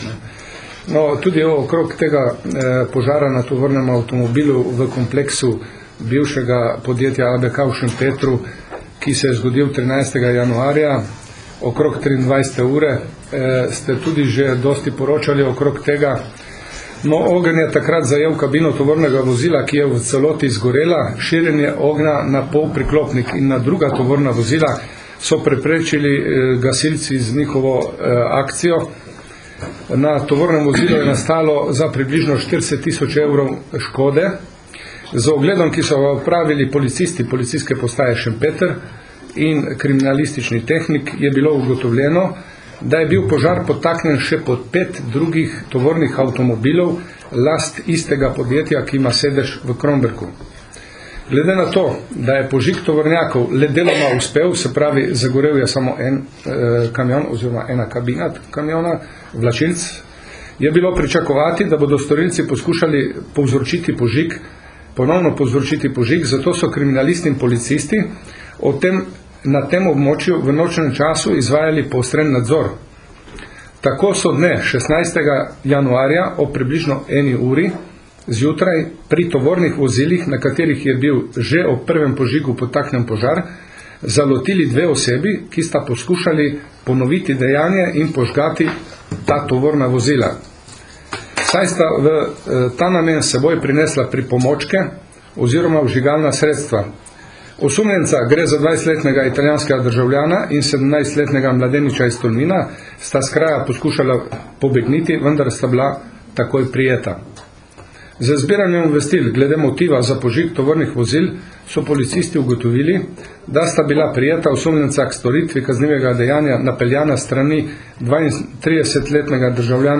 Policija - Odkrili požigalce tovornega vozila v Šempetru pri Gorici - informacija z novinarske konference PU Nova Gorica
Na novinarski konferenci 29. januarja 2010